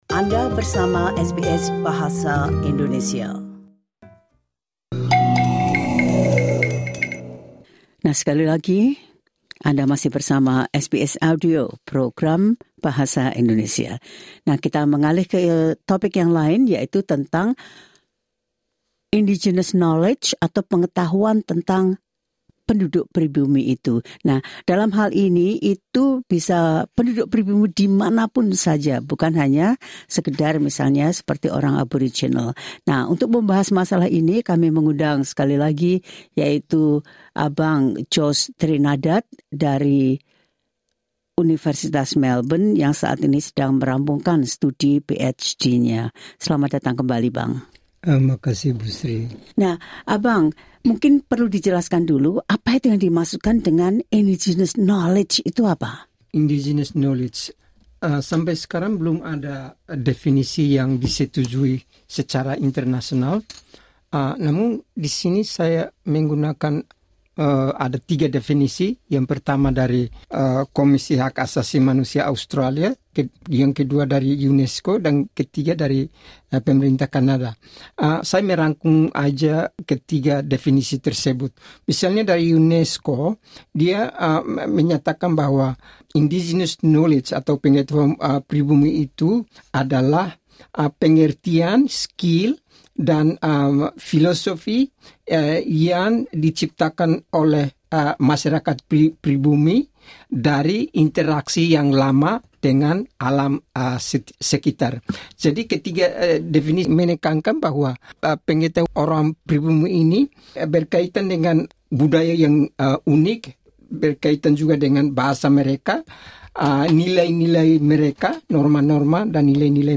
(SBS studio Melbourne - May 2025.)